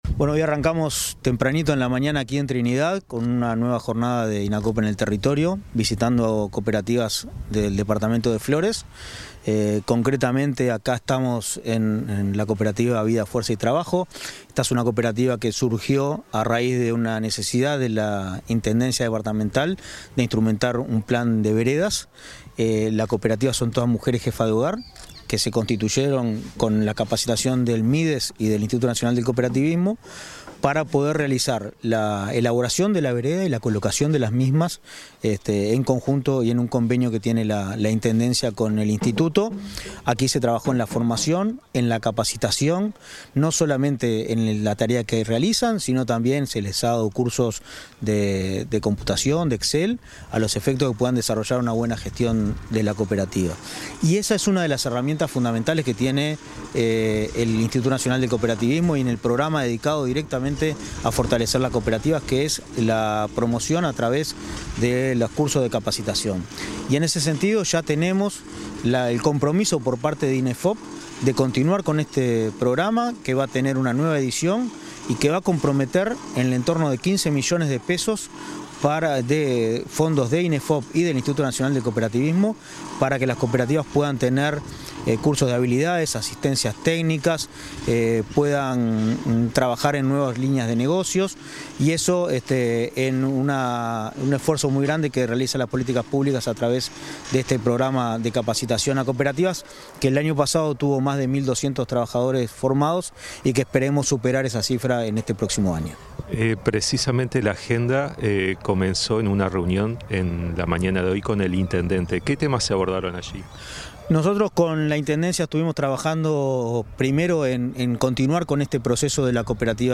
Entrevista al presidente del Inacoop, Martín Fernández
Entrevista al presidente del Inacoop, Martín Fernández 19/07/2023 Compartir Facebook X Copiar enlace WhatsApp LinkedIn El presidente del Instituto Nacional del Cooperativismo (Inacoop), Martín Fernández, dialogó con Comunicación Presidencial en Flores, durante una recorrida por ese departamento para difundir las herramientas de apoyo que brinda el organismo.